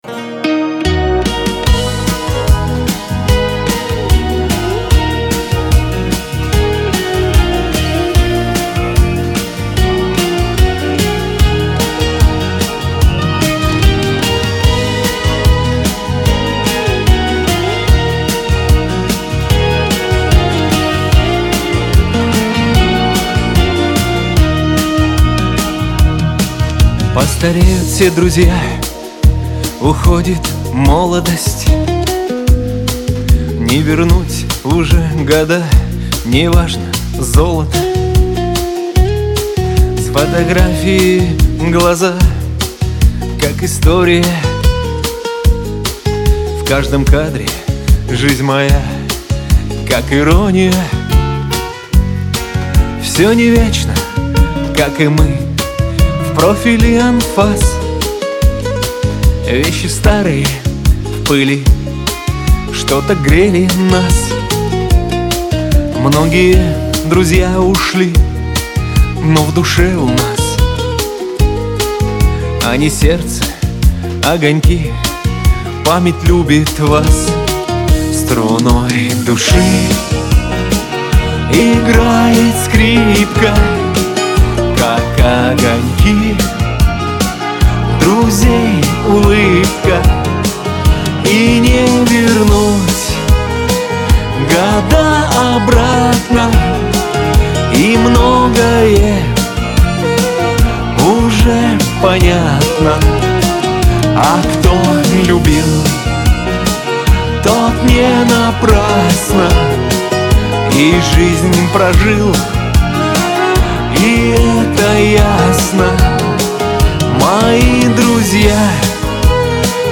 pop , Шансон , эстрада